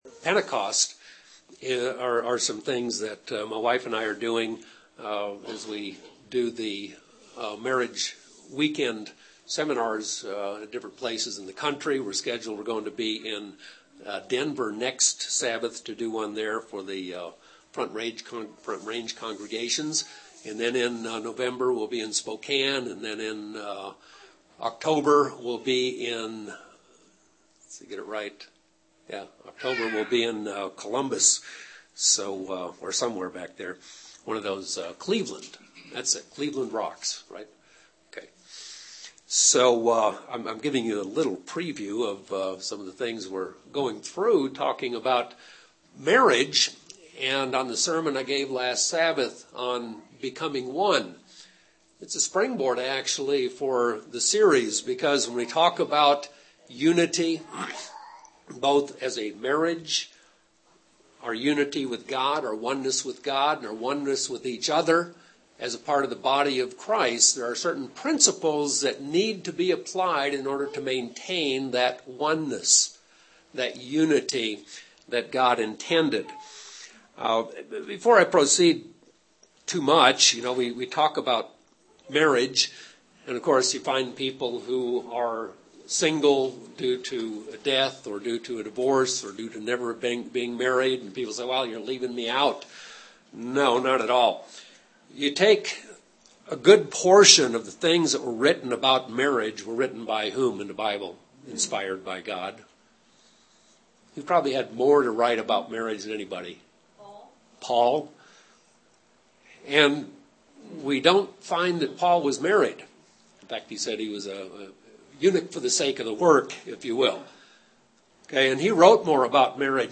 We are told to become one in marriage, one with God, and one with each other. Becoming one requires sacrifice. This sermon explores how and why.